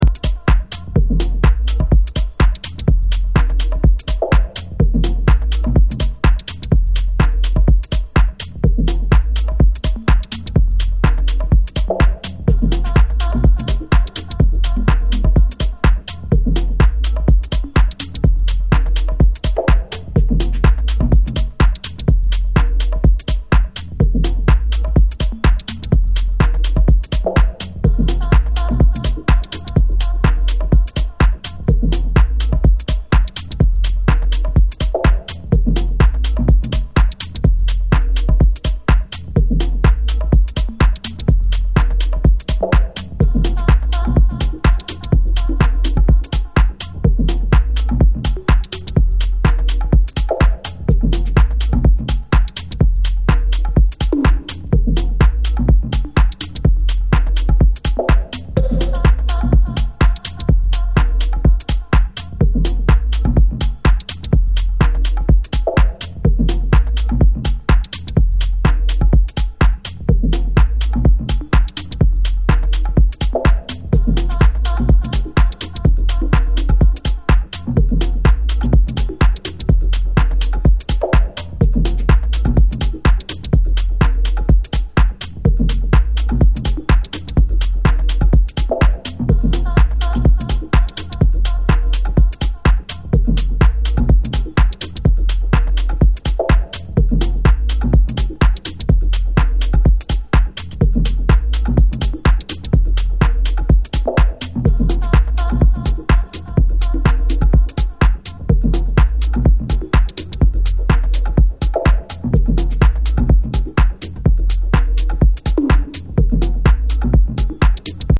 minimal and house cuts